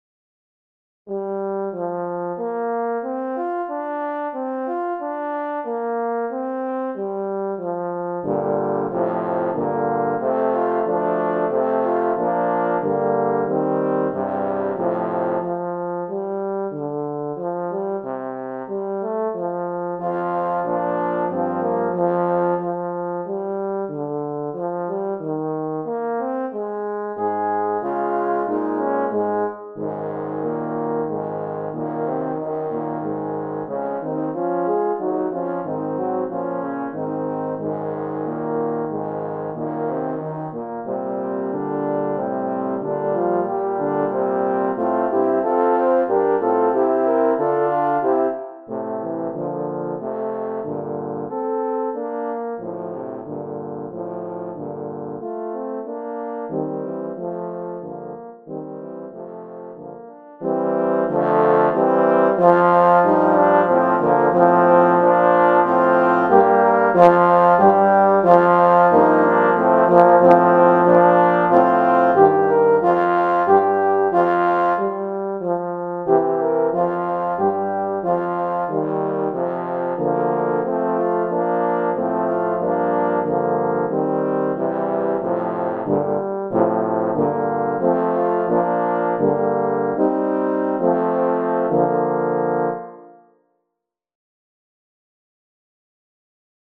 Voicing: Horn Quartet